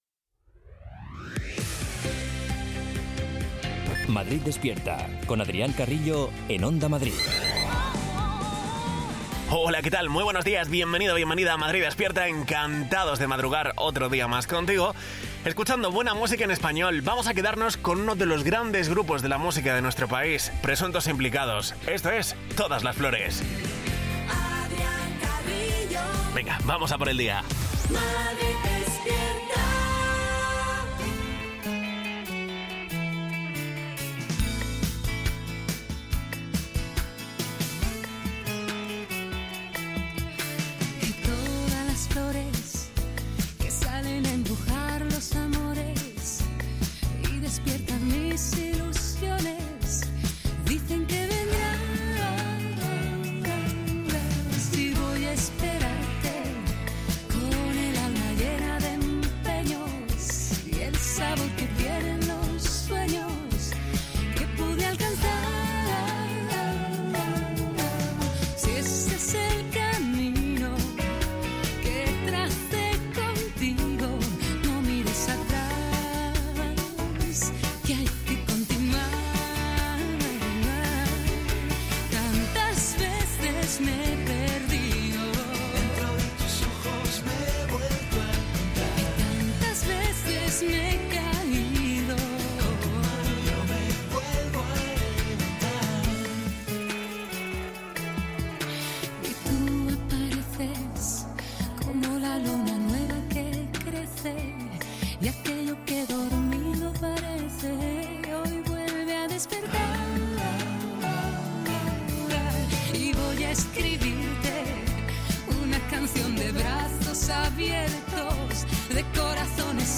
Morning show